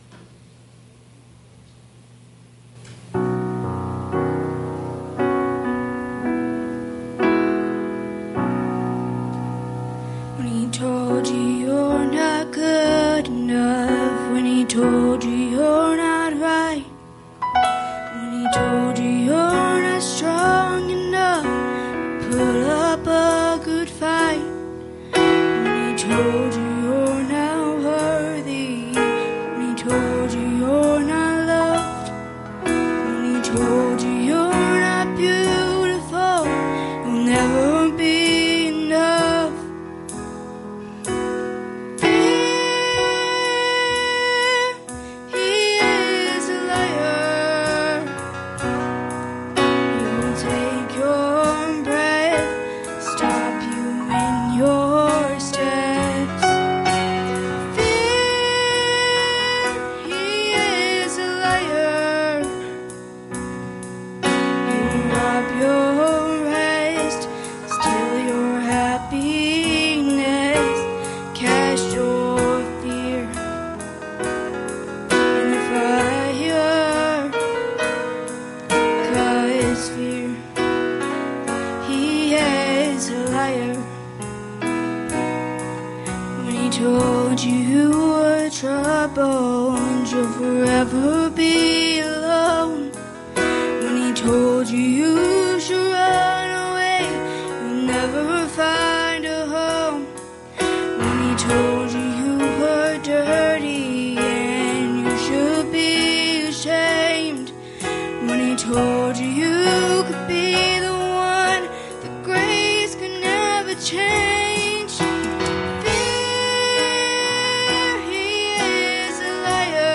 Church Age Study Passage: Revelation 2:8 Service Type: Sunday Evening “You see